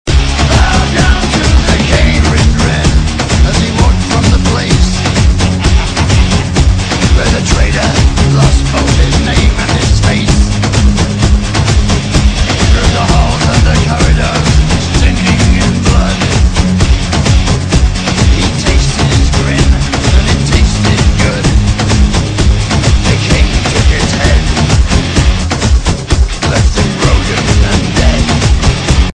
Mp3 Ringtones